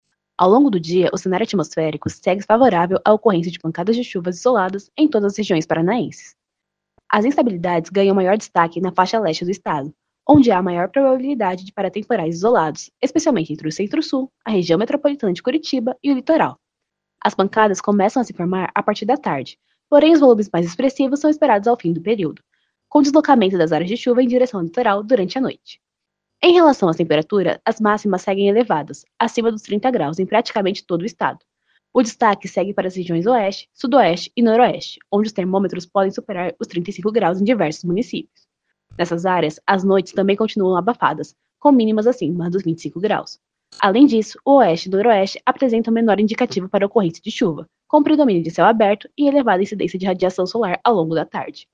Ouça o que diz a meteorologista